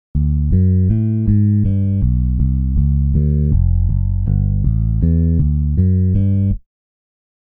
ジャズとかでよく聴くベースラインだよ。
ほら、四分音符ボンボンボンボン♪ってやるやつ。
ちょっと待って、ベースで弾いてみるから。